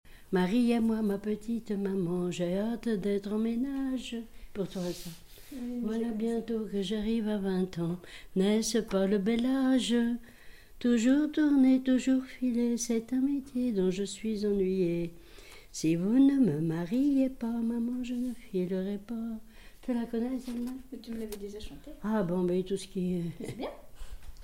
Saint-Valérien
Genre laisse
témoignage et chansons